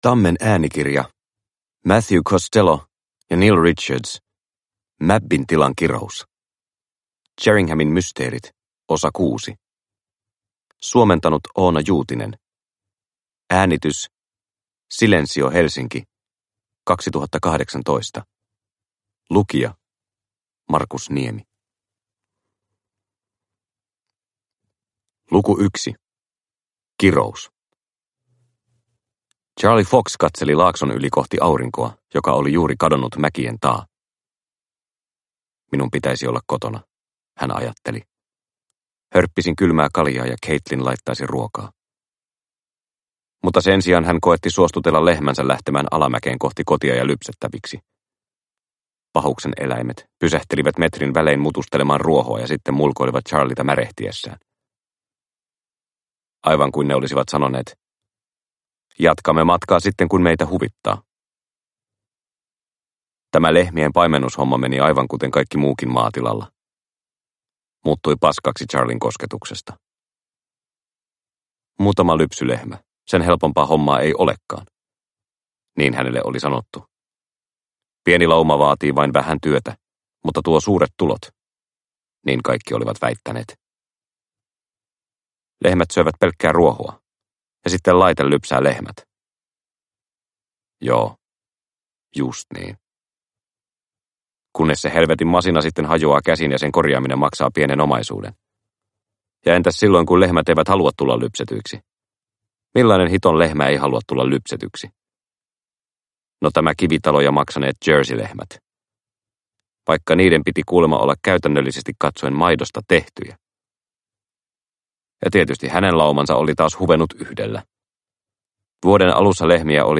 Mabbin tilan kirous – Ljudbok – Laddas ner